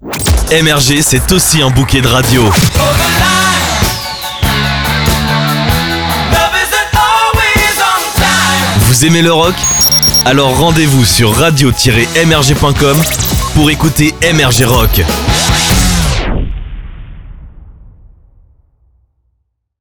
medleys (mini-mix)
• Réalisés par nos DJ producteurs